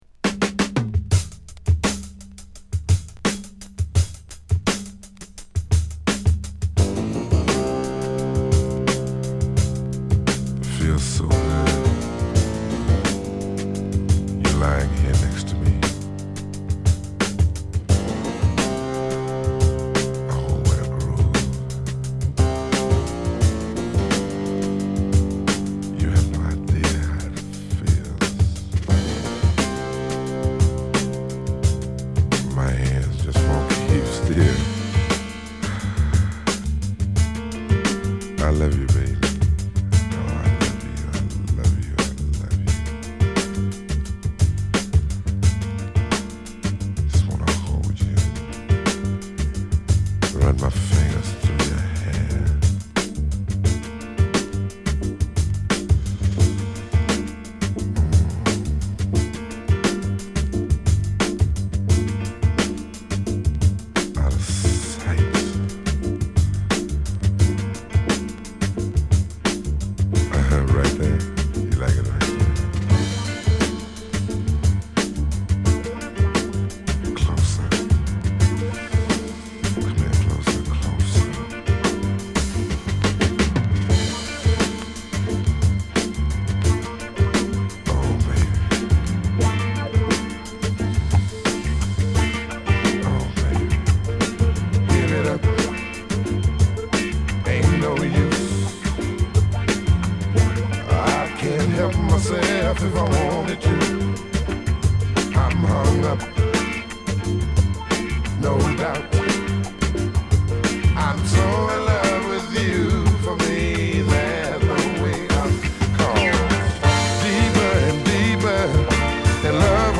得意のストリングス・アレンジが冴えるまくり
には迫力マンテン過ぎるDrum Breakが印象的。